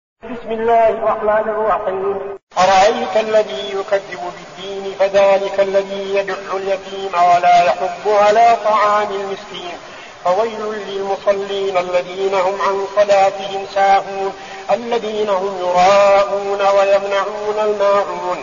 المكان: المسجد النبوي الشيخ: فضيلة الشيخ عبدالعزيز بن صالح فضيلة الشيخ عبدالعزيز بن صالح الماعون